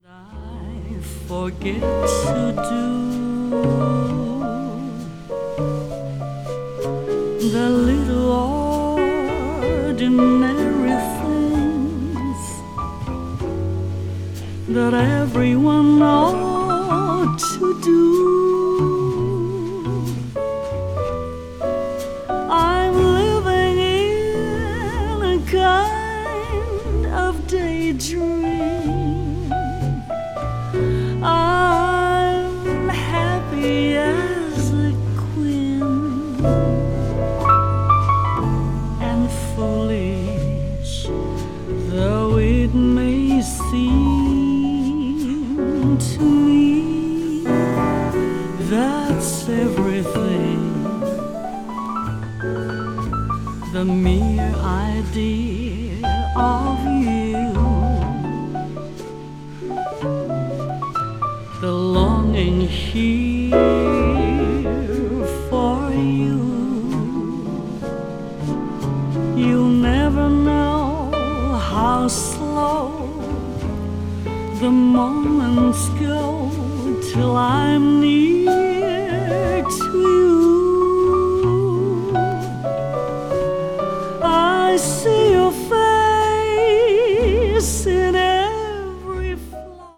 media : EX+/EX+(some slightly noise.)
Japanese jazz singer
japanese jazz   jazz standard   jazz vocal